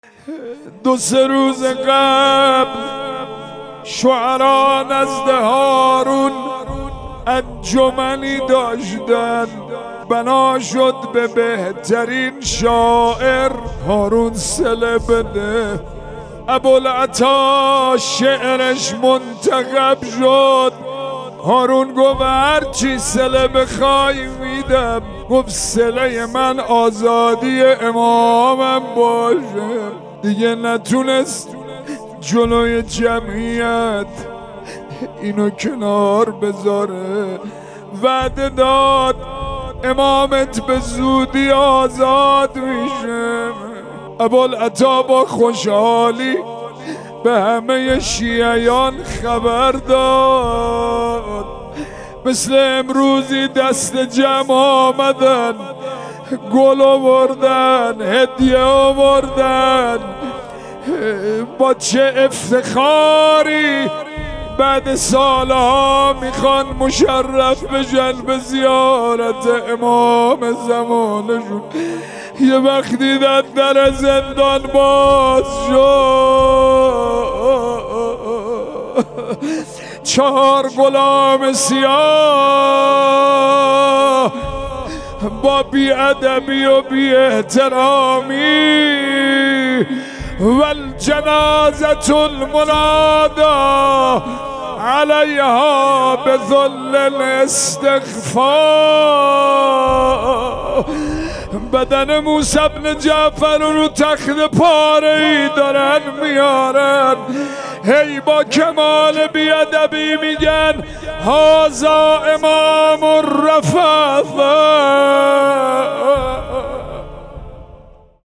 روضه موسی ابن جعفر
شهادت امام کاظم علیه السلام 1400 | دسته عزاداری هیئات مذهبی قم